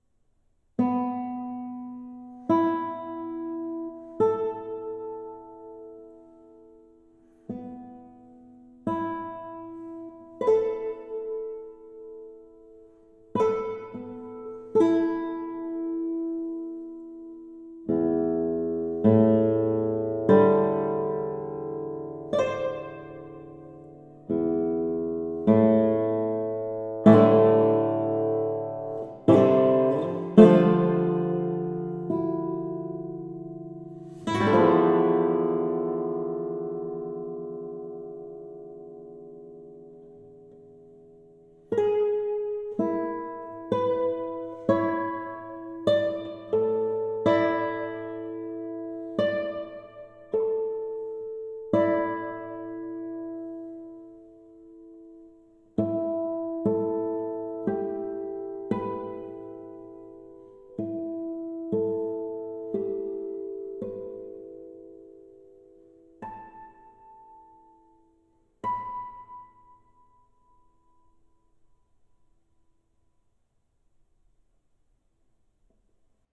guitar. I love playing simple music!